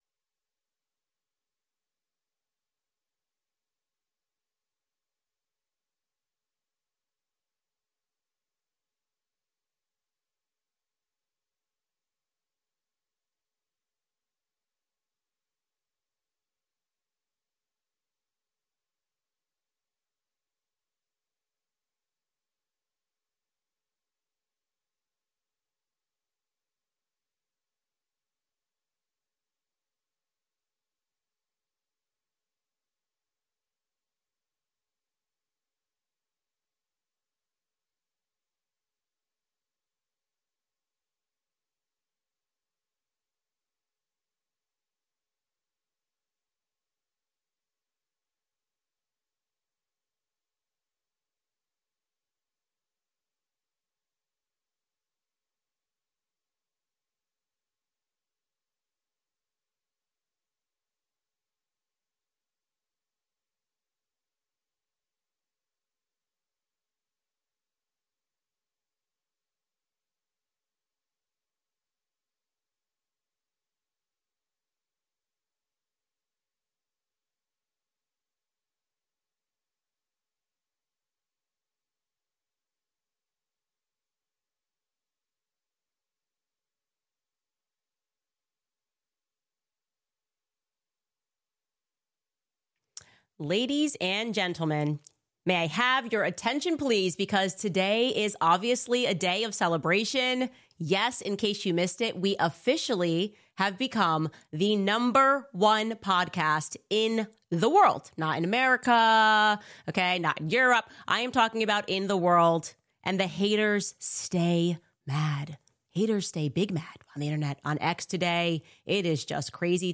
#1 Podcast Worldwide! Plus Erika Kirks Interview.